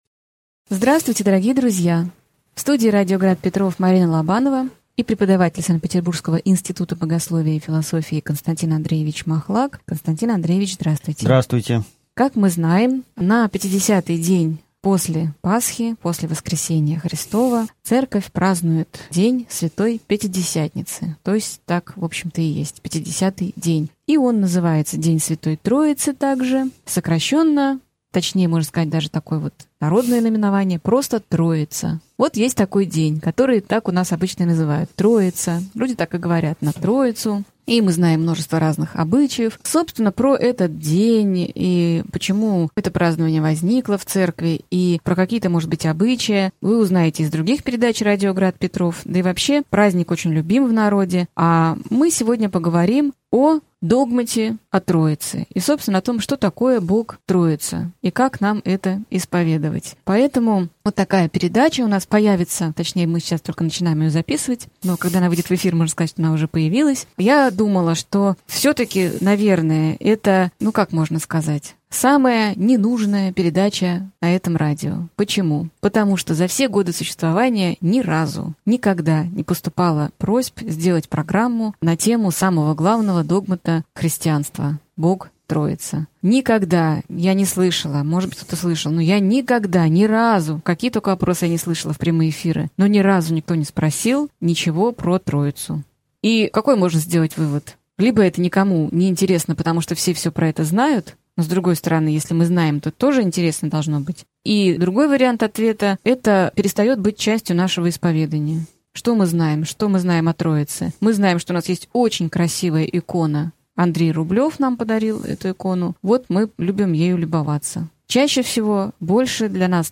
Аудиокнига Передача 1. Троица.